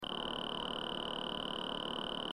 It gets louder when the volume increases.
mythticking.mp3